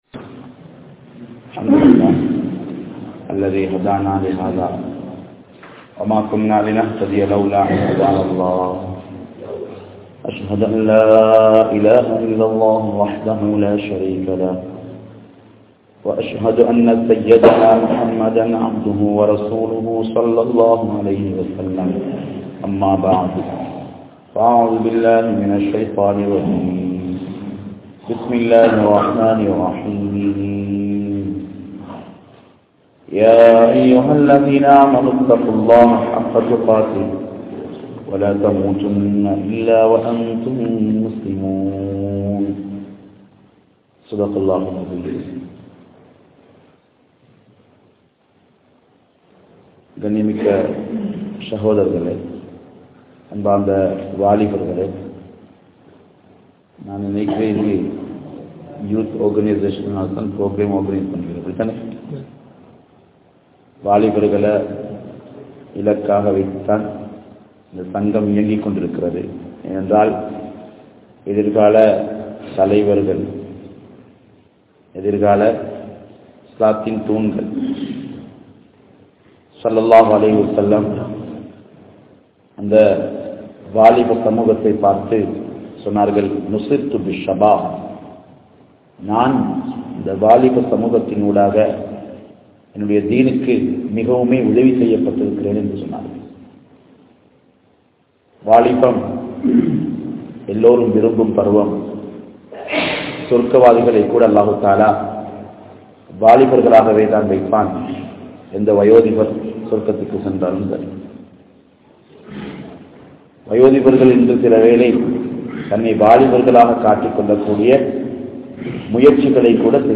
Indraya Vaalifarhalum Iruthi Mudivuhalum (இன்றைய வாலிபர்களும் இறுதி முடிவுகளும்) | Audio Bayans | All Ceylon Muslim Youth Community | Addalaichenai
Kandy, Udathala vinna, Al-Hiqma Jumua Masjith